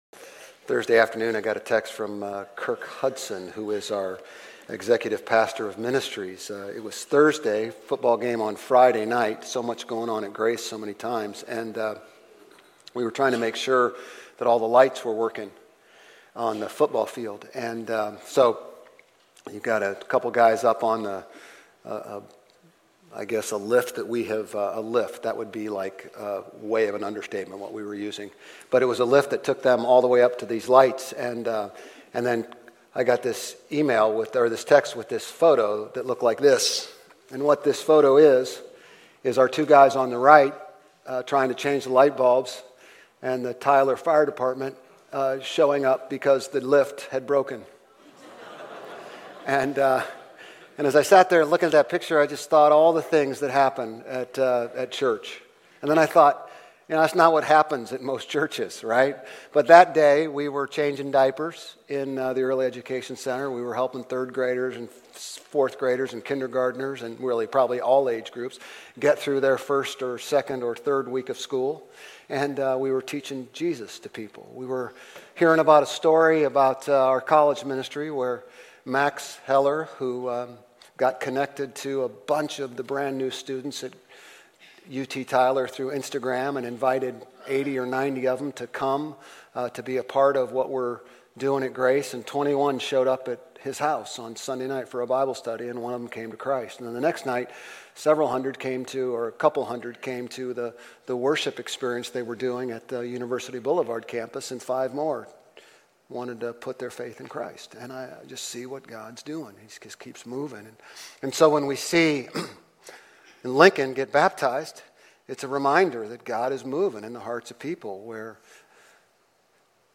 Grace Community Church Old Jacksonville Campus Sermons Genesis 1:26-27 - Sanctity of Life Sep 02 2024 | 00:30:34 1x 00:00 / 00:30:34 Subscribe Share RSS Feed Share Link Embed